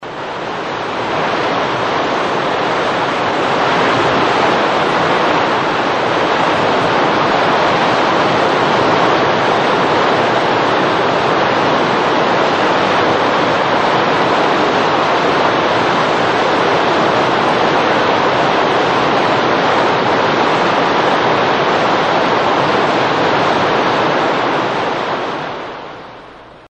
Звуки водопада
На этой странице собраны лучшие записи звуков водопадов разной интенсивности: от мощных потоков до нежных ручьев.